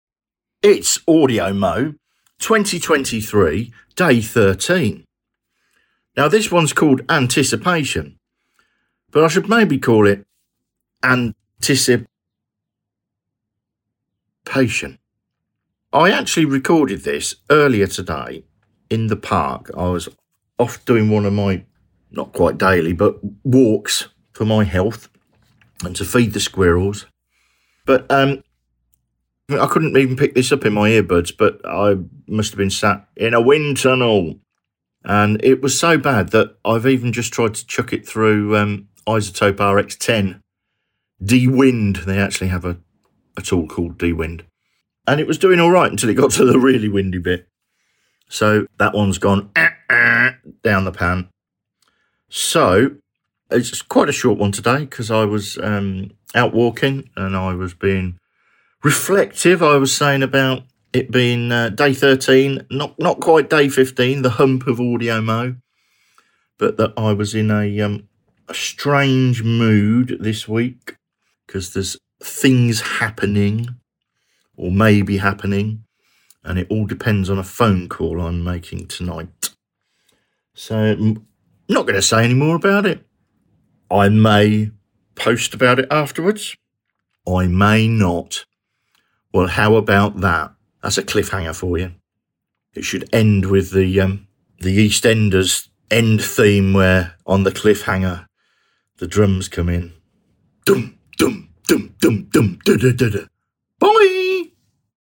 A re-record to cover a windy failure ...